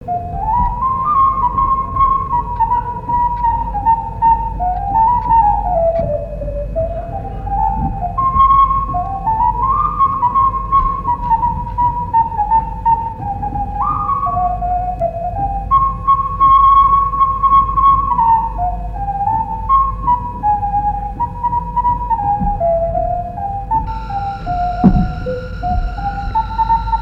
danse : mazurka
Assises du Folklore
Pièce musicale inédite